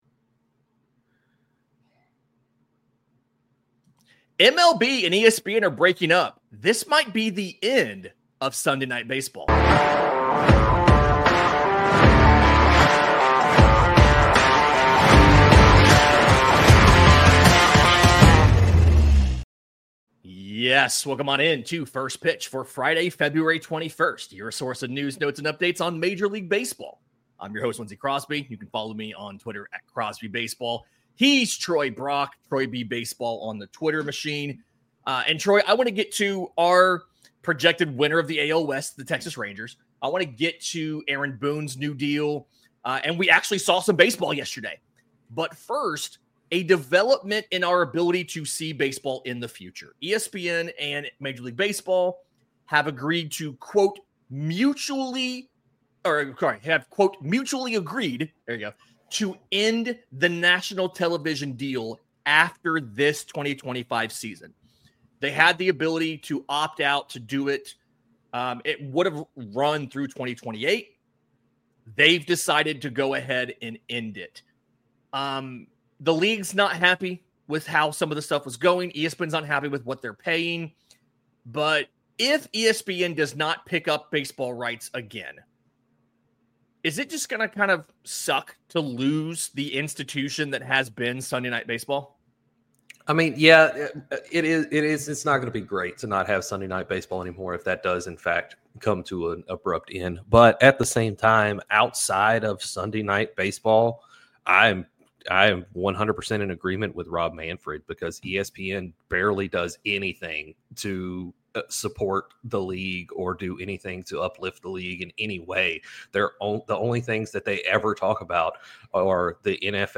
The boys discuss the news of ESPN and MLB mutually agreeing to end their contract after this season, the breaking news of the New York Yankees dropping their facial hair policy, and previewing the Texas Rangers and their high risk, high reward rotation.